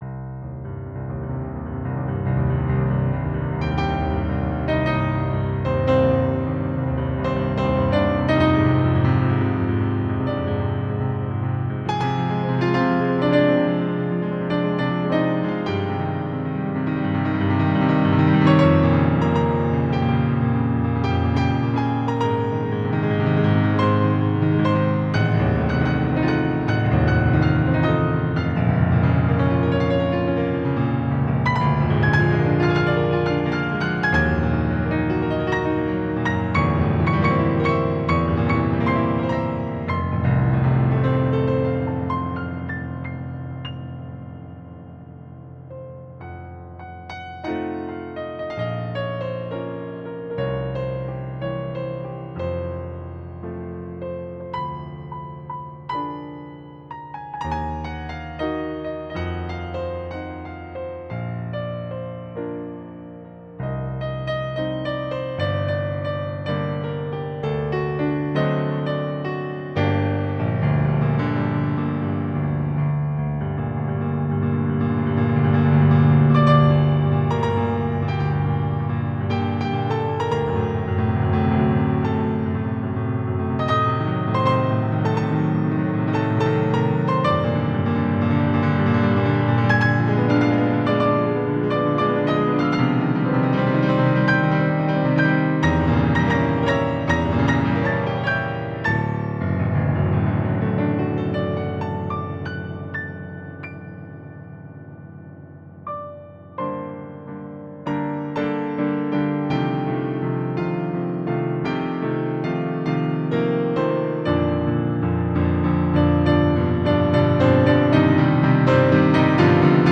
Mein Haupt-Hobby, Klavierkompositionen: